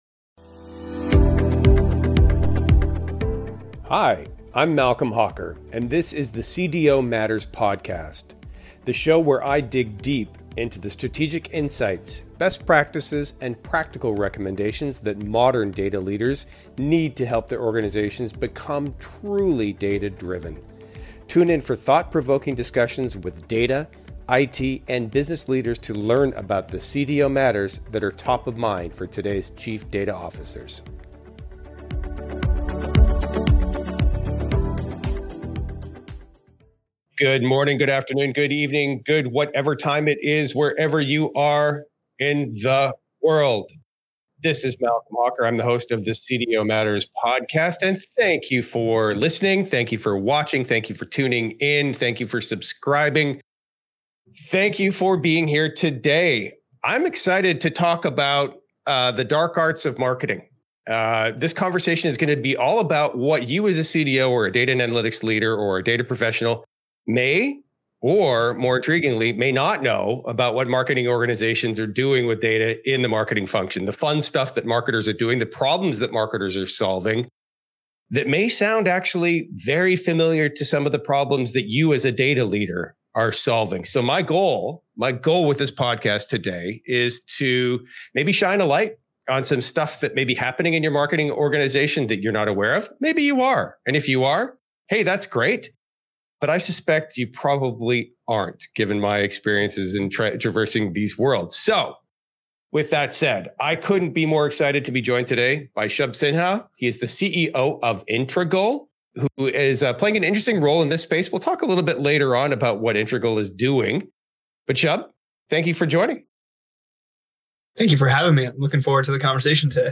interviews on thought leaders on data fabrics, blockchain and more